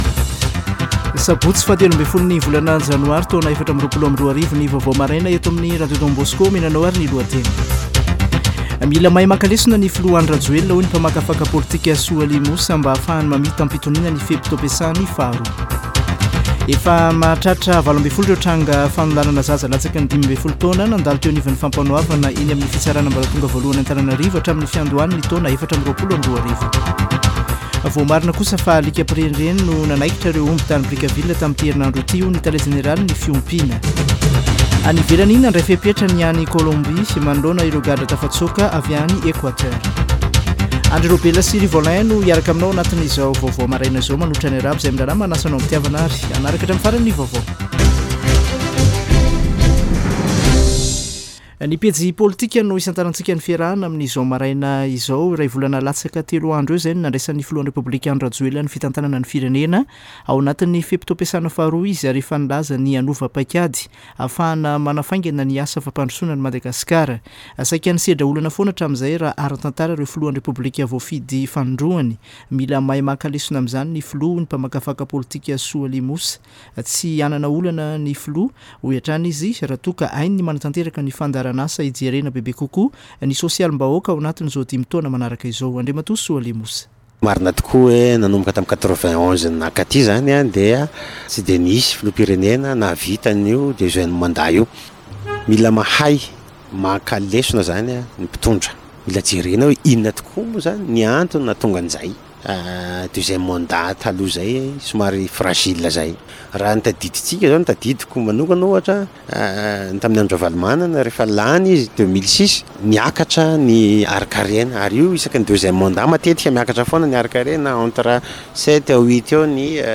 [Vaovao maraina] Sabotsy 13 janoary 2024